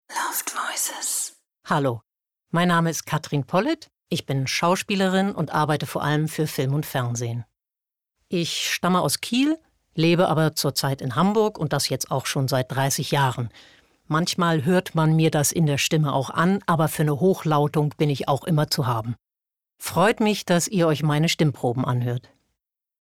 markant
Norddeutsch
Presentation